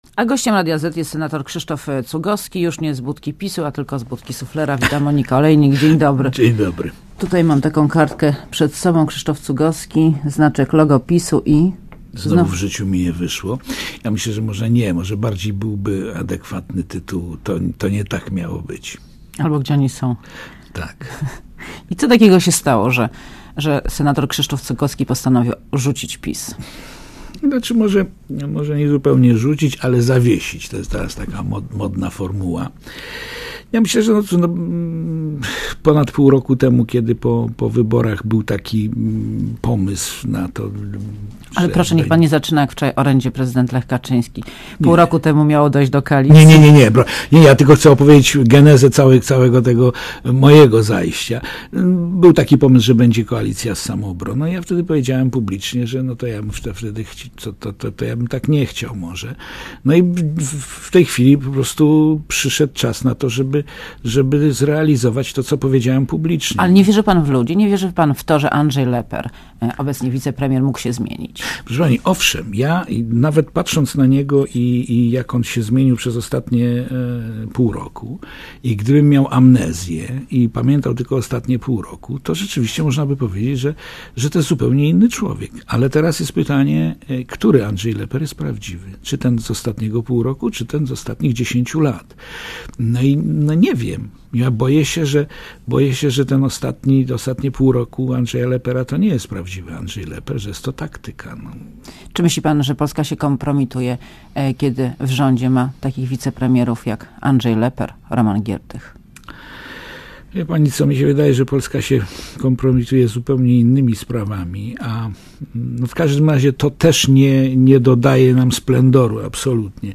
Monika Olejnik rozmawia z Krzysztofem Cugowskim, senatorem, który zawiesił członkostwo w PiS